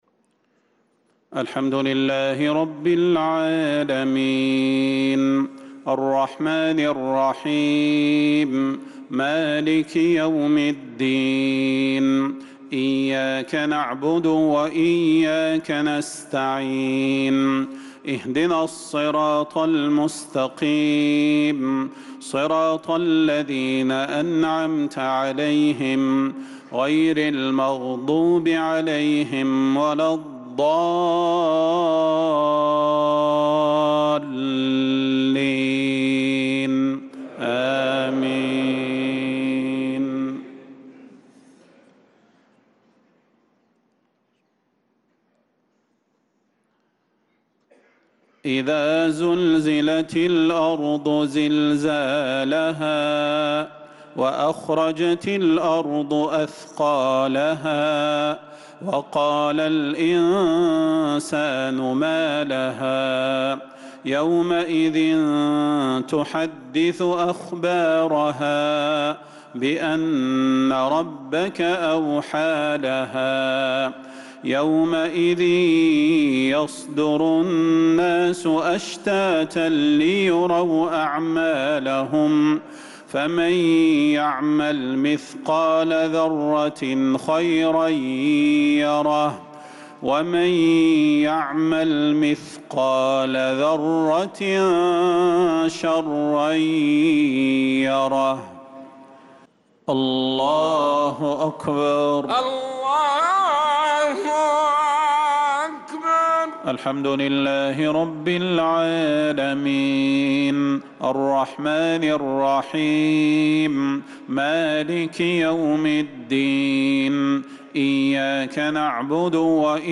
صلاة المغرب للقارئ صلاح البدير 12 شوال 1445 هـ
تِلَاوَات الْحَرَمَيْن .